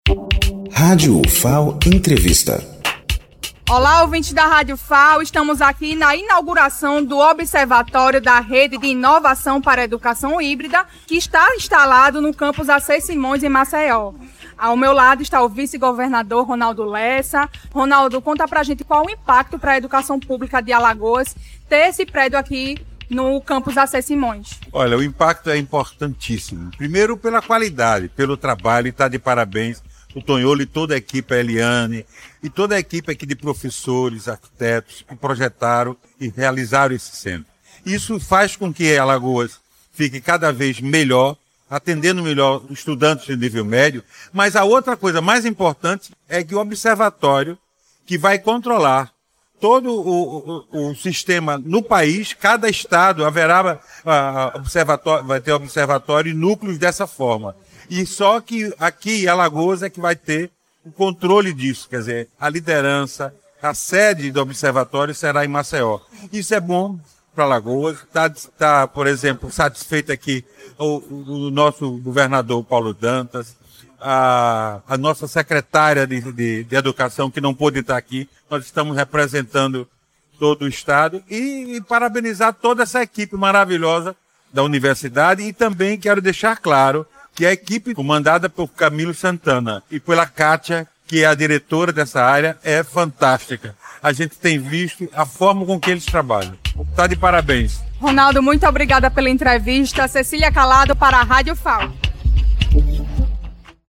Entrevista com o vice-governador Ronaldo Lessa.